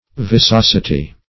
viciosity - definition of viciosity - synonyms, pronunciation, spelling from Free Dictionary Search Result for " viciosity" : The Collaborative International Dictionary of English v.0.48: Viciosity \Vi`ci*os"i*ty\, n. Vitiosity.